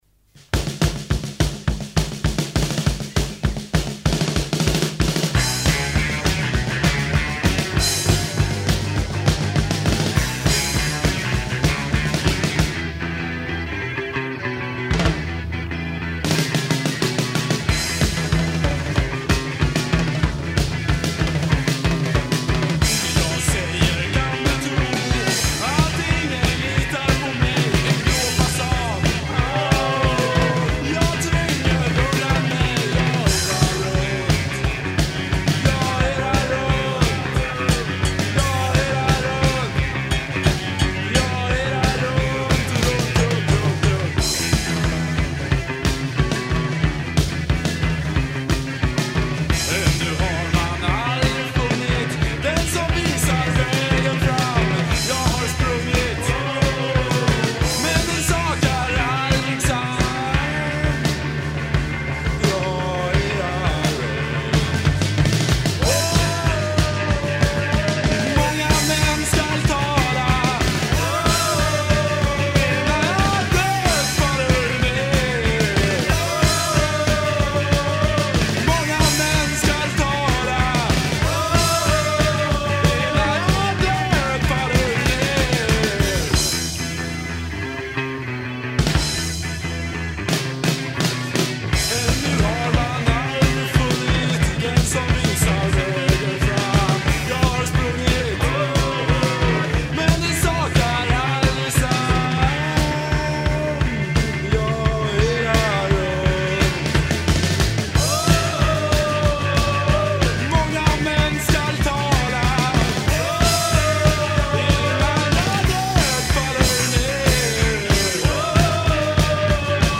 Bass, b-Stimme
Gitarre, b-Stimme
Trommel